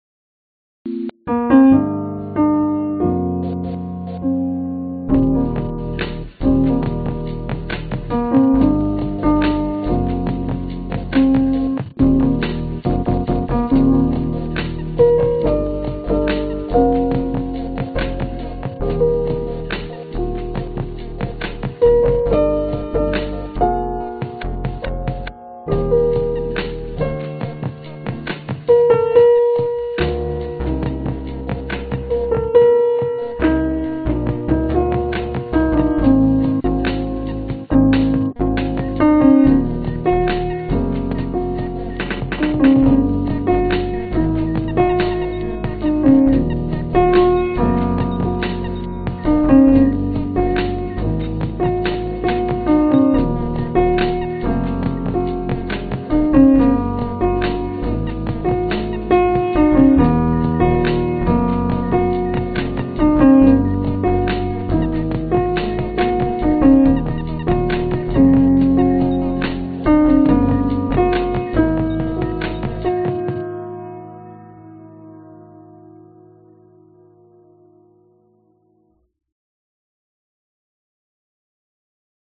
描述：使用Bitwig和Pianoteq，对钢琴和鼓的主干进行切片，并在钢琴上添加一些音高偏移，非常有趣。
Tag: 器乐 钢琴 贝司 合成器 循环播放